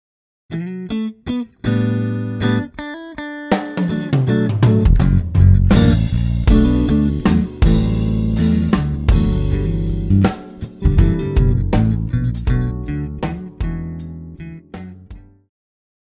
爵士,流行
電吉他
樂團
演奏曲
靈魂爵士樂,放克,節奏與藍調,靈魂
獨奏與伴奏
有節拍器
is a slow and sexy soul funk tune.
Guitar 吉他
Trumpet 小號
Tenor Sax 次中音薩克斯
Trombone 長號
Keyboards 鍵盤
Bass 貝斯
Drums 鼓
Percussion 打擊樂器